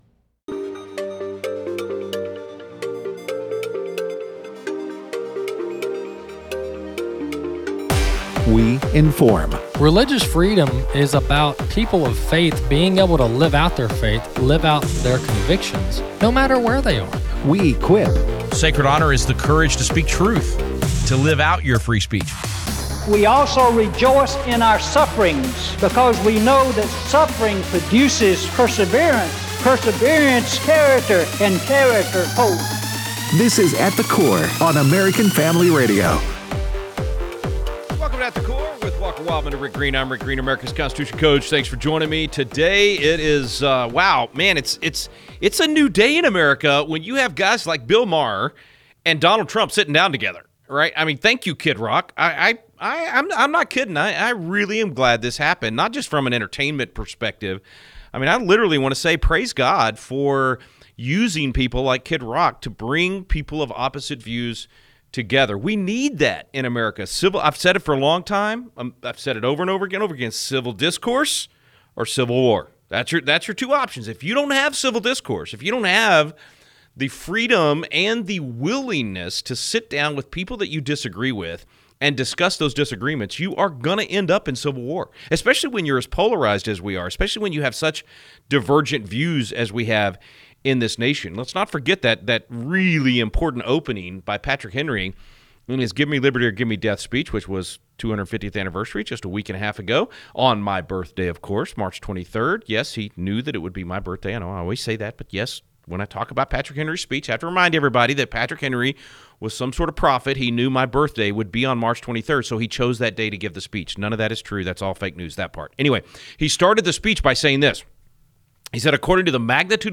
Callers share about the tariffs